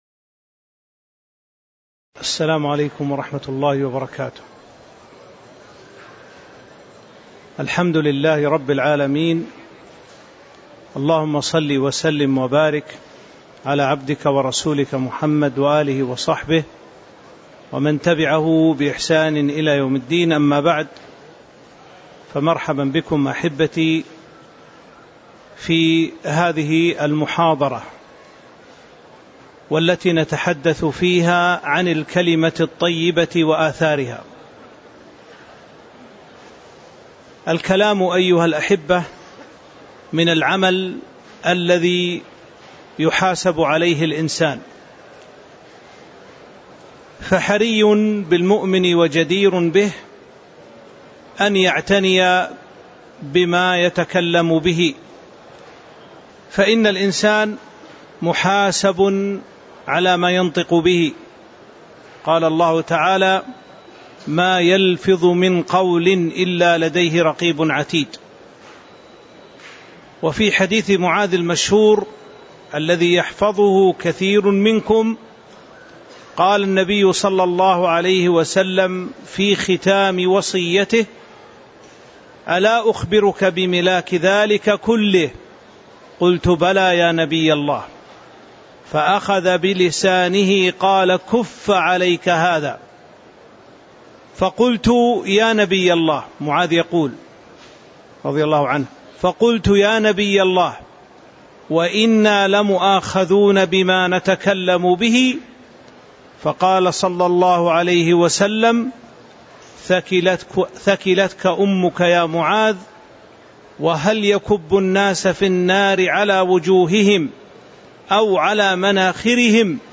تاريخ النشر ٤ جمادى الأولى ١٤٤٥ هـ المكان: المسجد النبوي الشيخ